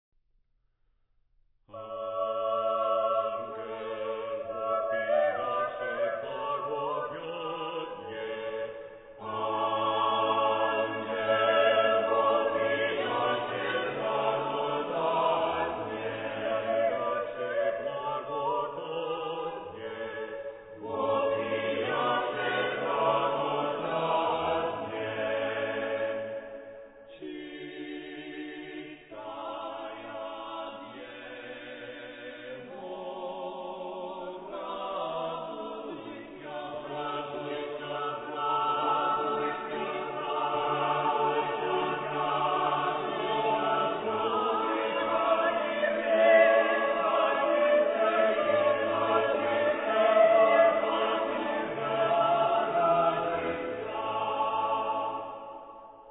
ソプラノがさらに３つに分かれ、アルトが３つ、テノール３つ、バス３つ、合計１２声部にも分かれています。